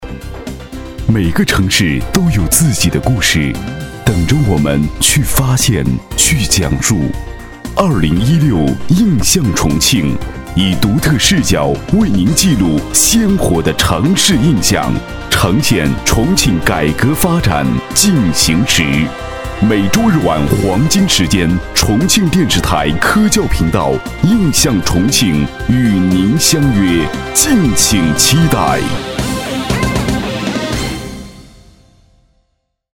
B男106号
【片头】《印象重庆》男106-大气浑厚
【片头】《印象重庆》男106-大气浑厚.mp3